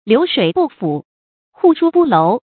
注音：ㄌㄧㄨˊ ㄕㄨㄟˇ ㄅㄨˋ ㄈㄨˇ ，ㄏㄨˋ ㄕㄨ ㄅㄨˋ ㄌㄡˊ